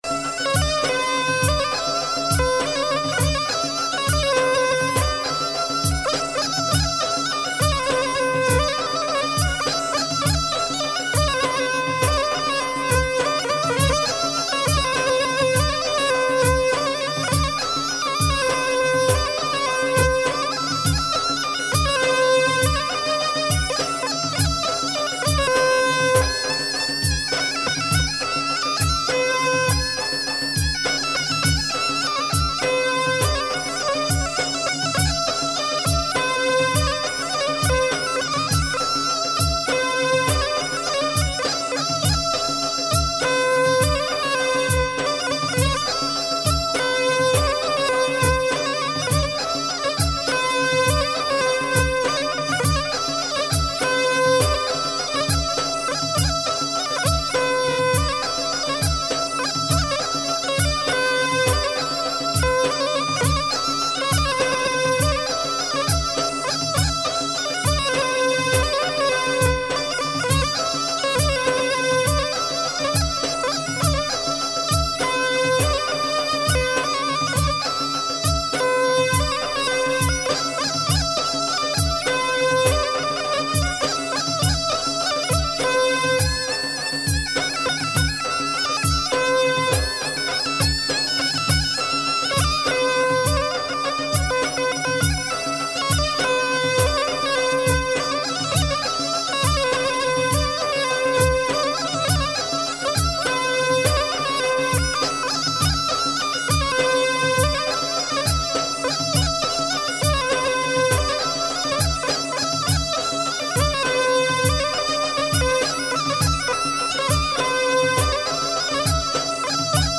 اجرای بسیار زیبا و با کیفیت ساز اصیل خراسانی قوشمه
بی کلام مخصوص رقص
قوشمه خراسان